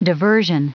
Prononciation du mot diversion en anglais (fichier audio)
Prononciation du mot : diversion